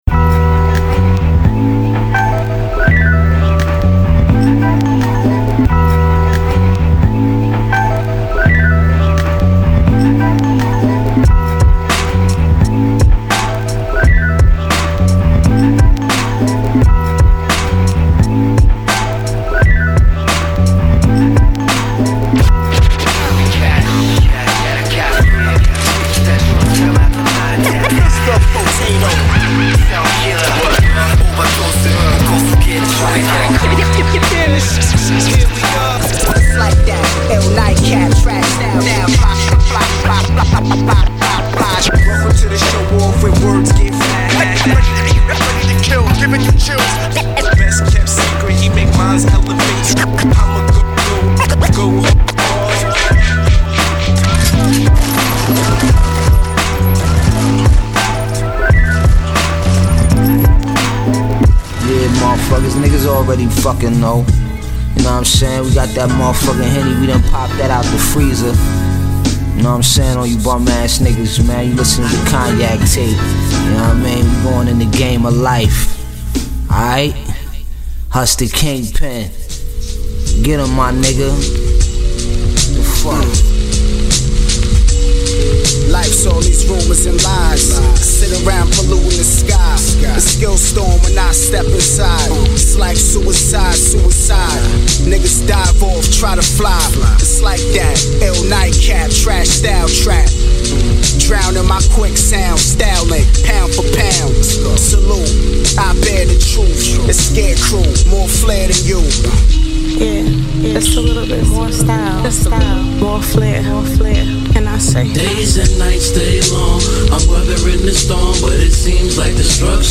渋いHIPHOPとサイケデリックなHIPHOPの交差する点を見事に捉えた作品です。
＊試聴は前半数曲です。